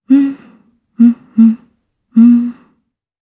Ripine-Vox_Hum_jp.wav